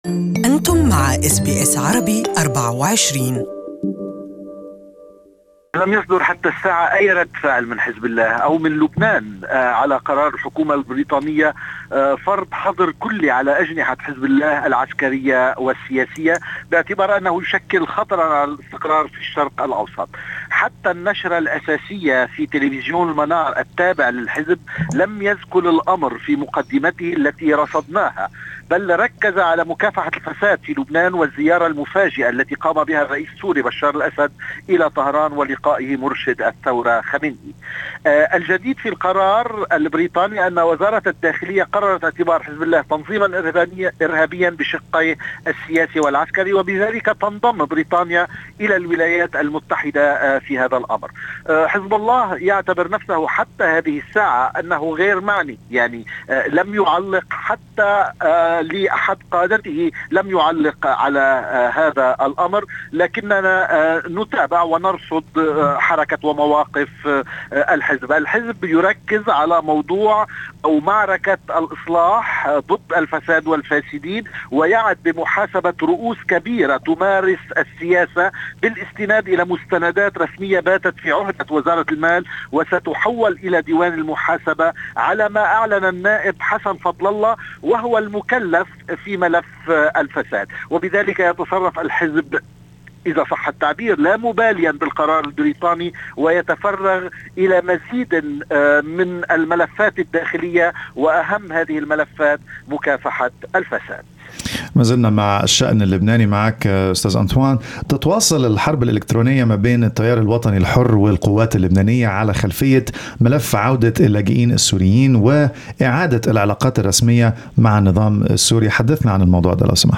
More is in this report.